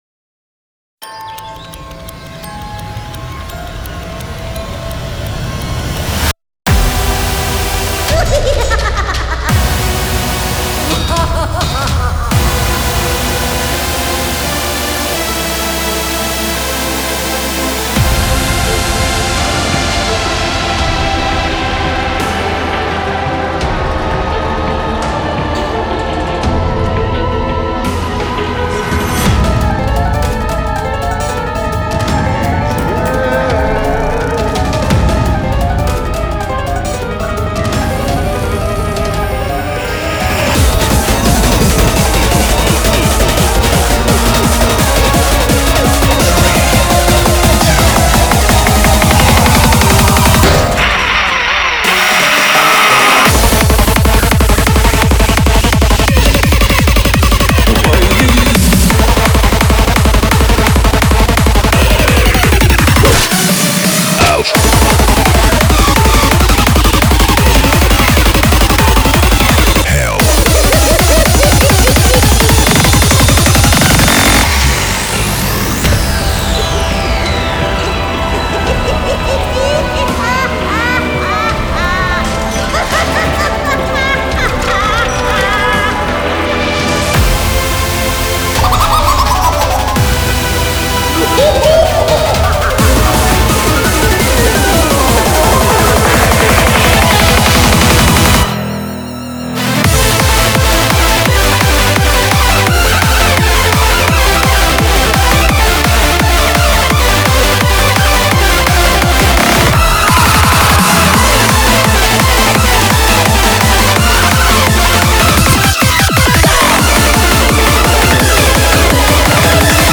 Audio QualityPerfect (High Quality)